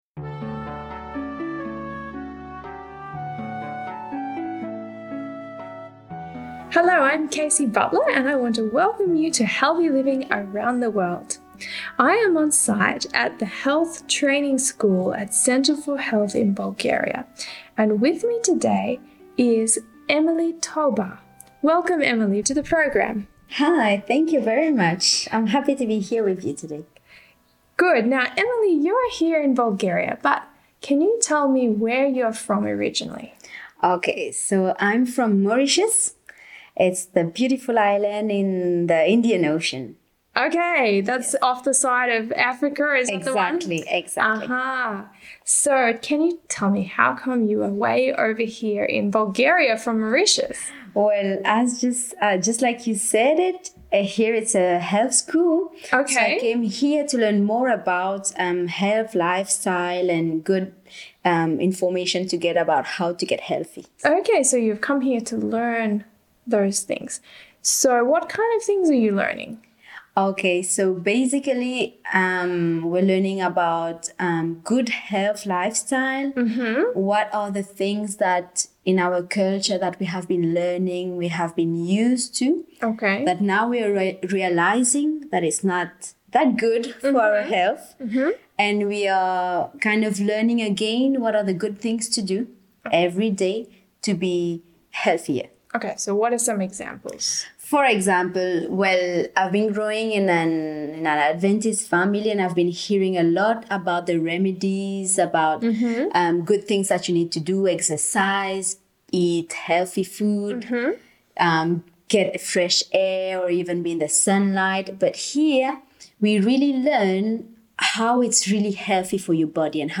Healthy Living Around the World Interview